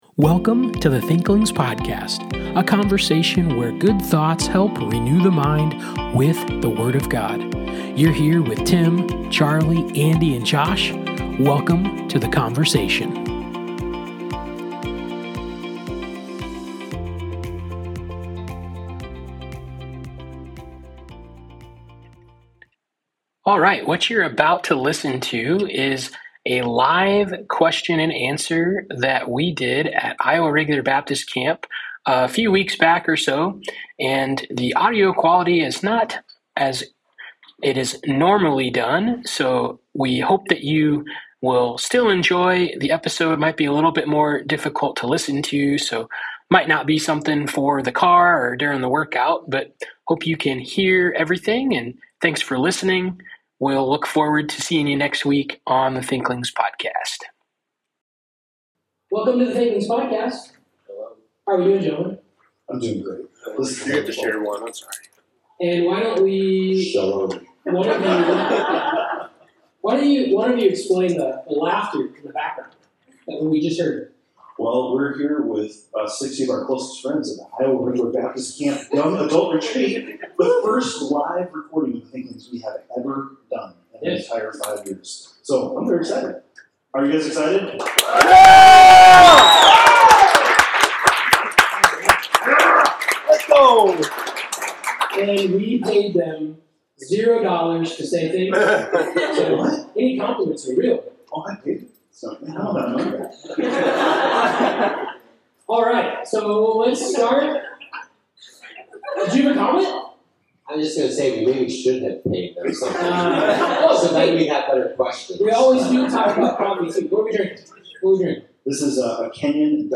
This week’s episode is a special TALQ (Thinklings Answer Listener Questions) recorded live at the IRBC Young Adult Retreat in Ventura, Iowa! Surrounded by good coffee, great company, and thoughtful questions, the Thinklings take time to engage with listeners and explore real-life applications of faith, theology, and literature.